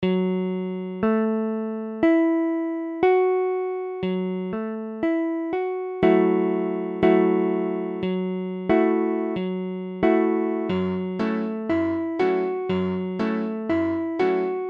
Illustration sonore : Gbm7.mp3
Tablature Gbm7.abcGbm7m7 : accord de Sol bémol mineur septième
Mesure : 4/4
Tempo : 1/4=60
A la guitare, on réalise souvent les accords en plaçant la tierce à l'octave.
Forme fondamentale : tonique quinte septième mineure tierce mineure
Gbm7.mp3